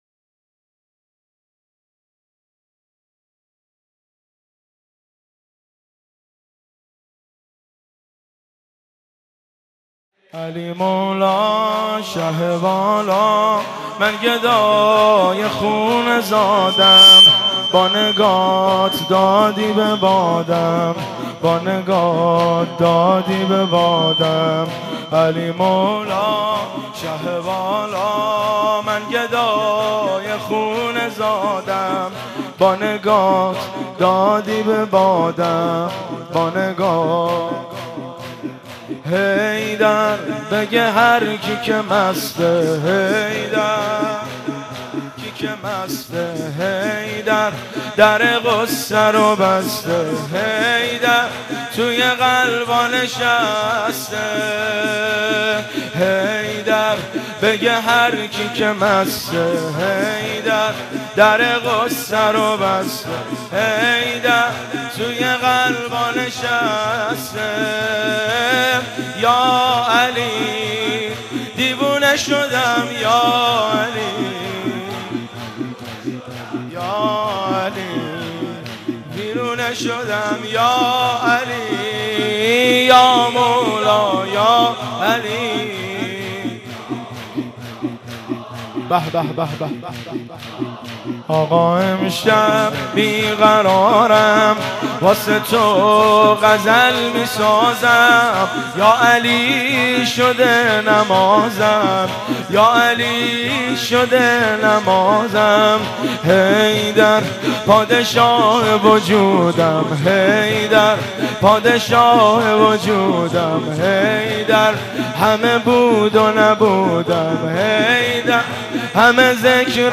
روضه و ذکر